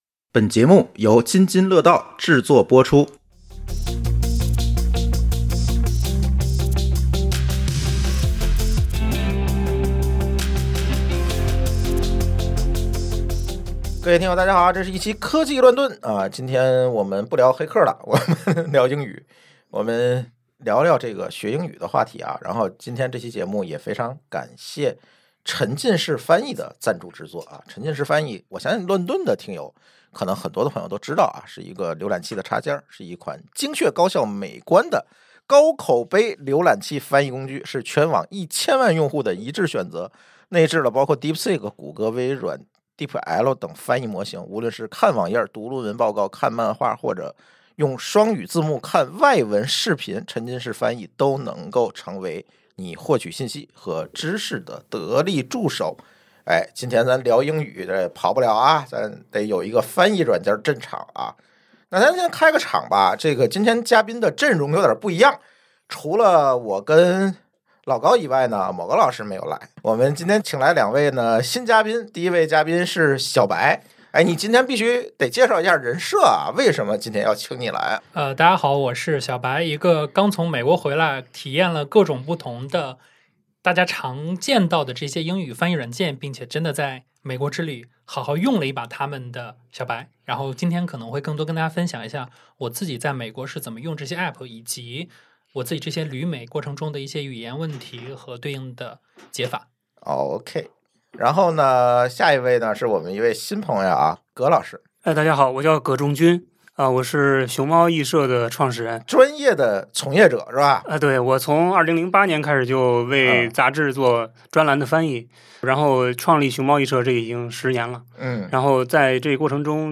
AI翻译工具越来越强大：实时翻译网页、生肉漫画、外语会议……甚至能“秒懂”外语梗。但这是否意味着我们从此可以躺平，彻底告别背单词、练听力？本期《科技乱炖》邀请四位身份迥异的嘉宾——翻译专家、英语小白、AI深度用户，以及亲历AI辅助跨文化交流的新生代，展开一场犀利又有趣的辩论：